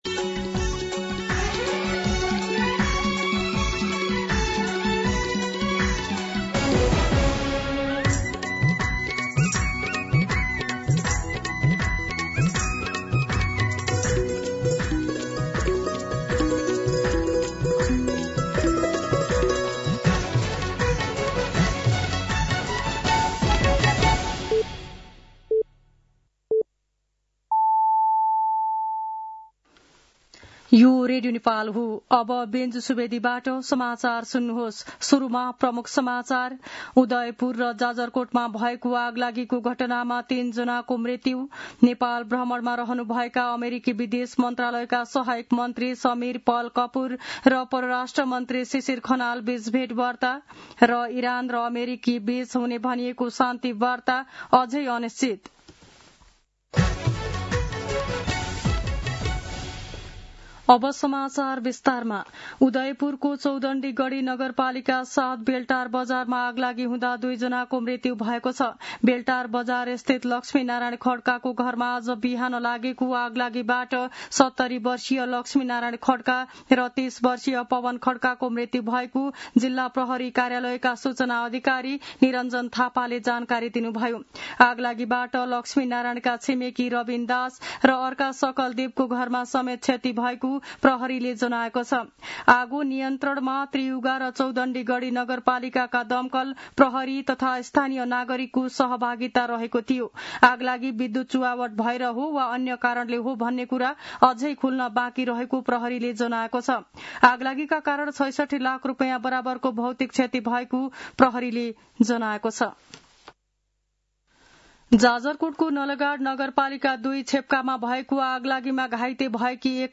दिउँसो ३ बजेको नेपाली समाचार : ८ वैशाख , २०८३